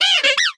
Grito de Glameow.ogg
Grito_de_Glameow.ogg.mp3